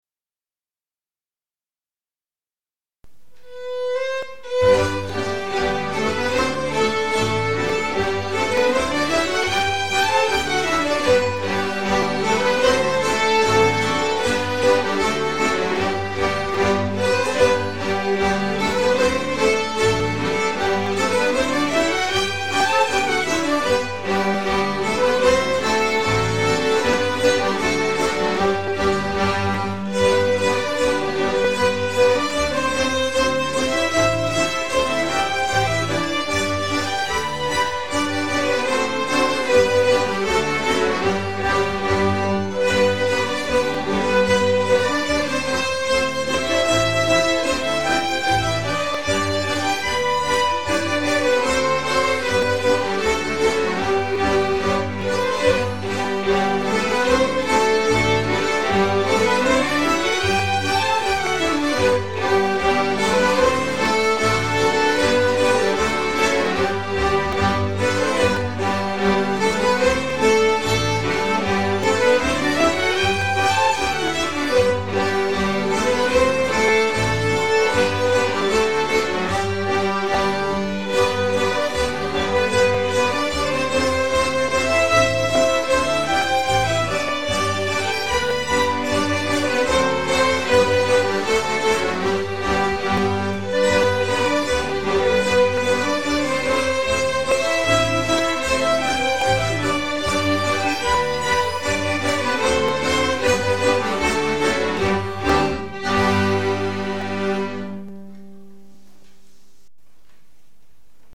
Allspelslåtar Slottsskogsstämman 2025